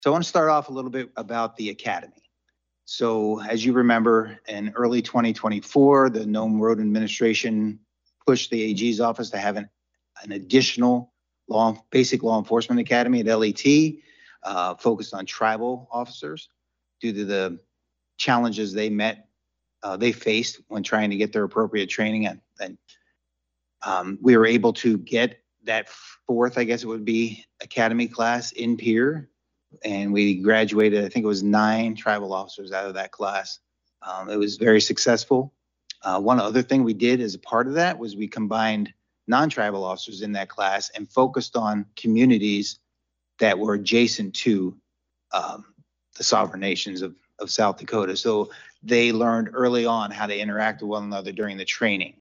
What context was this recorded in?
AGENCY VILLAGE, S.D.(HubCityRadio)- On Thursday, the first meeting of the Interim State Tribal Relations Committee was held at the Sisseton-Wahpeton Oyate Headquarters at Agency Village.